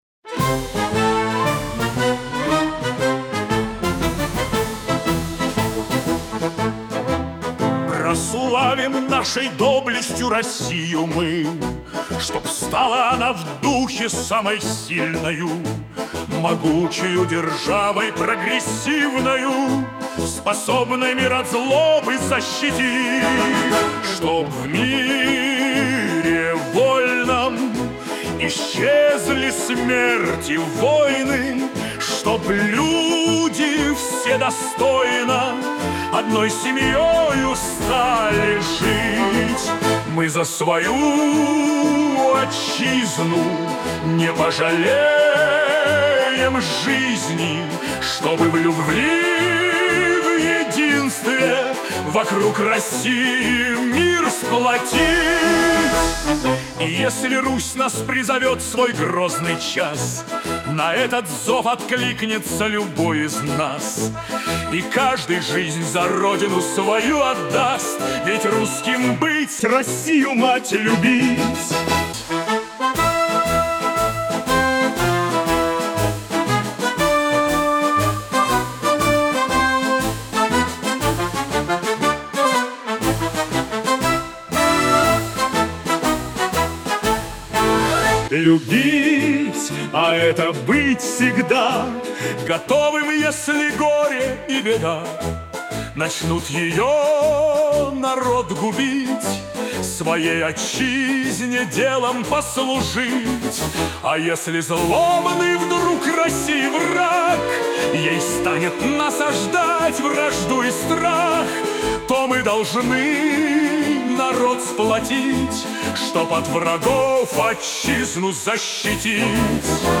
на мотив марша